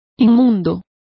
Complete with pronunciation of the translation of filthy.